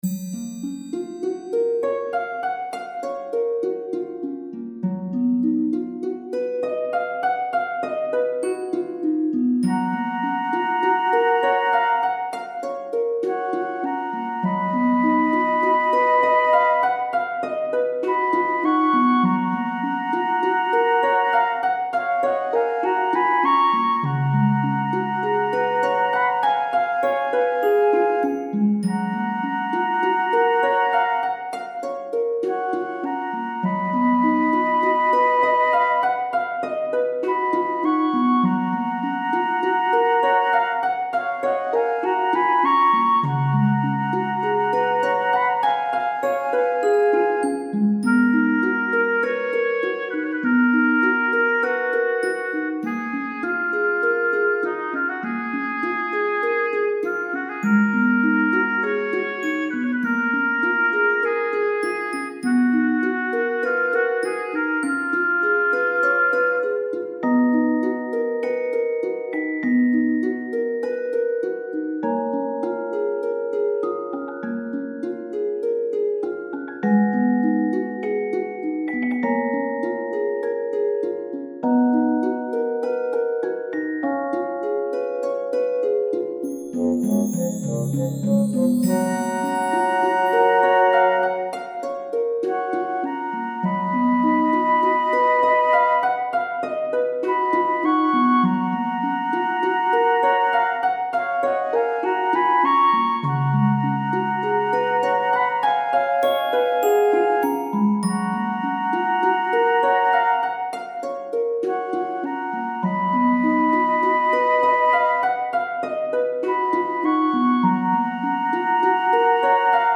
ファンタジー系フリーBGM｜ゲーム・動画・TRPGなどに！
実は全然和風じゃない楽器のチョイスです。